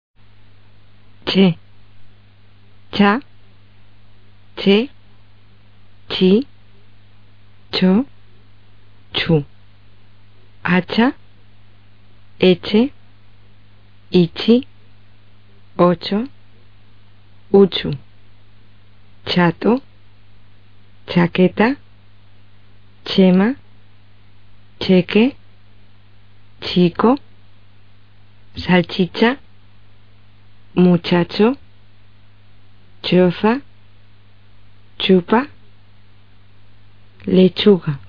【c】是舌前前硬腭塞擦清辅音。发音时，舌面前部顶住前硬腭。气流冲开阻碍发出擦音。声带不振动。
Ch的发音：